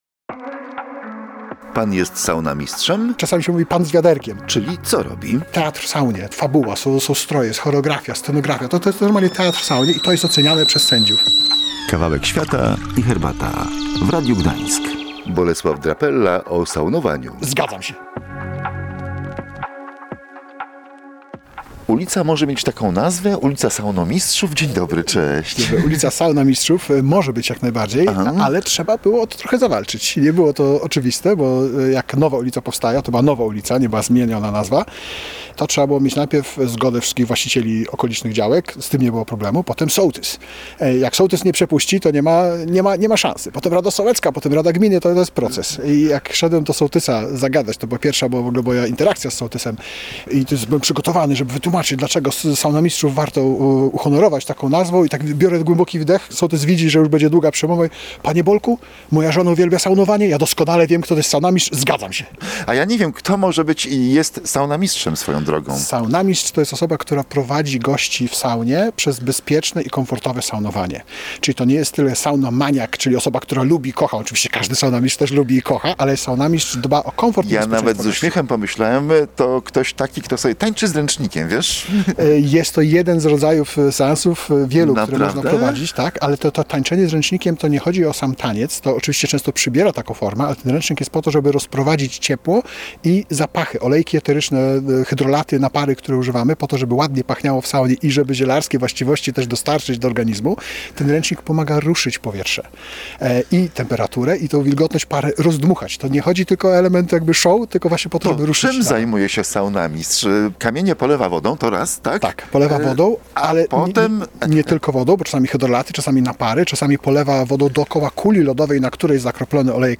Posłuchaj rozmowy w Saunowym Stawie: